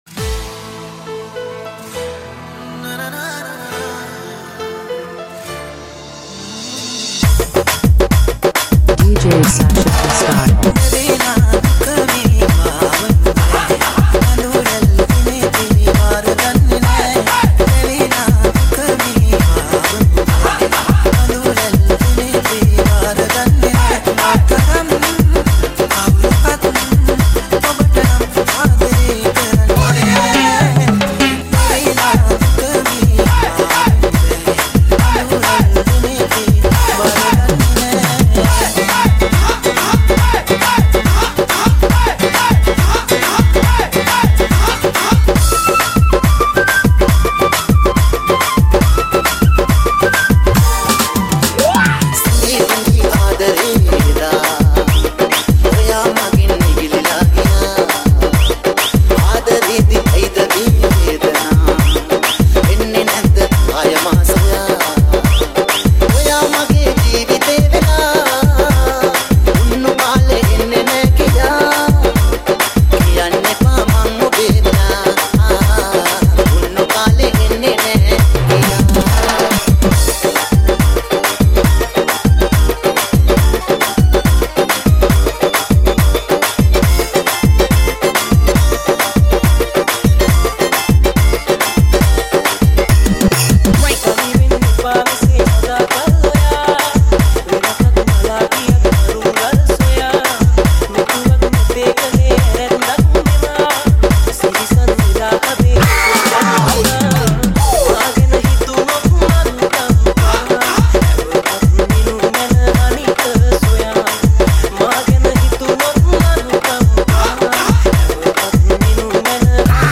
High quality Sri Lankan remix MP3 (9.5).